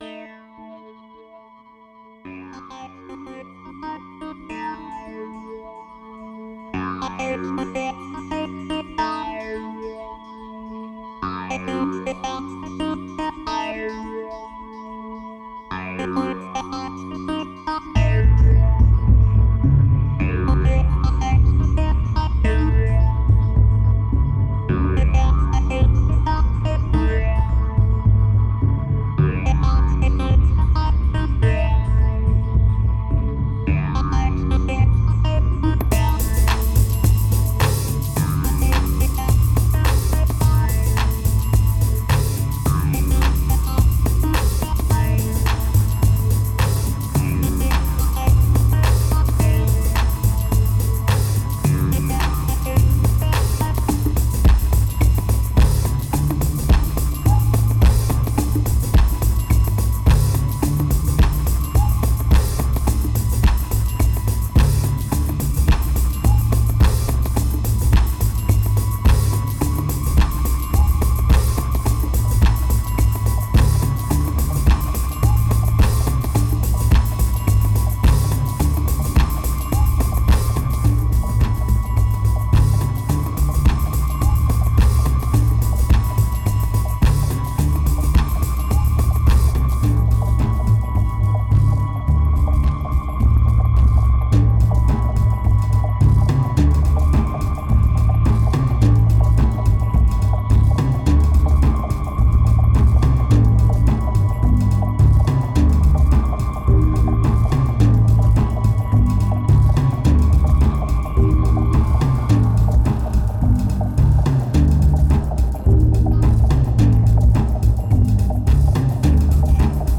2208📈 - -6%🤔 - 107BPM🔊 - 2011-04-09📅 - -220🌟